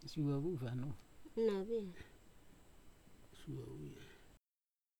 I tried it out on a recording where I had three informants talking, and one of them whispered his way through it.
Normally I’d normalise the file, so the levels are probably a little low in the original, but also normalising looks at the whole file, not individual sections of the file, so if someone was yelling later in the recording, the softer sections would basically stay the same.